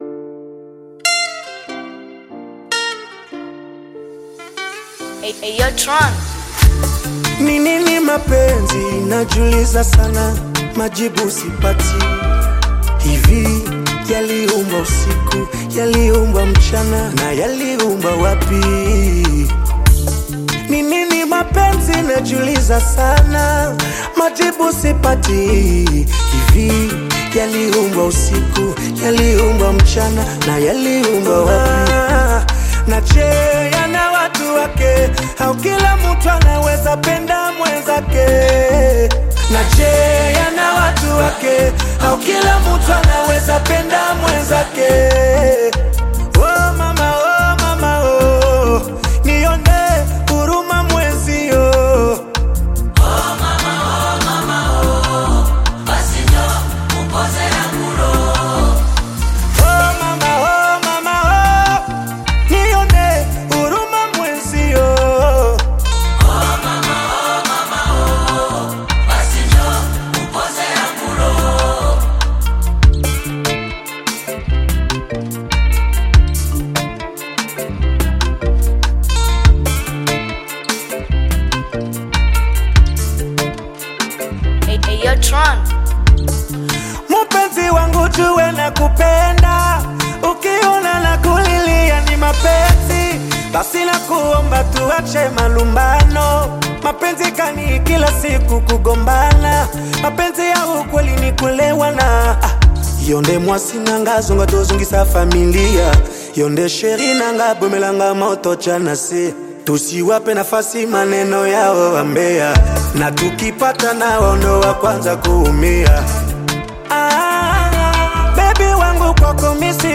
Tanzanian Bongo Flava Rhumba